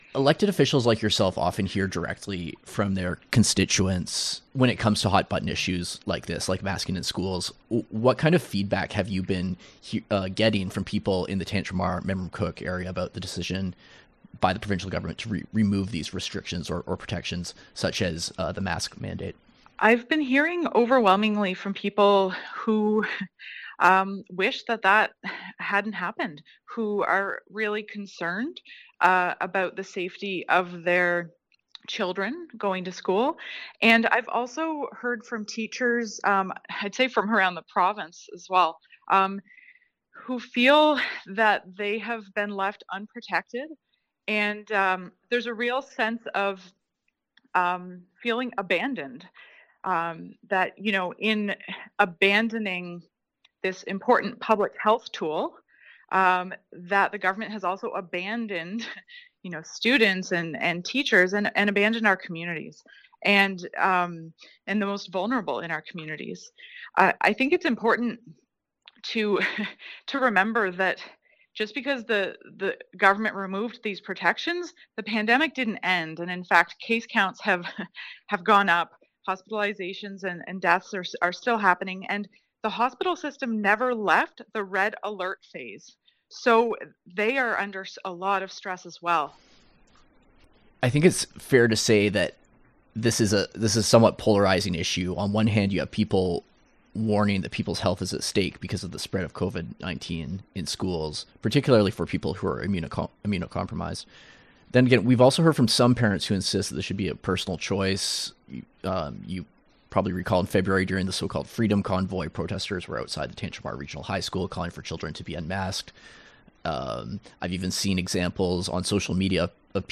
For more on this story, CHMA spoke to Memramcook-Tantramar MLA Megan Mitton, the Green Party’s health and education critic.